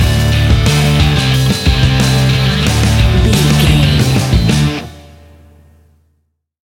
Ionian/Major
D
energetic
driving
heavy
aggressive
electric guitar
bass guitar
drums
hard rock
heavy metal
distortion
distorted guitars
hammond organ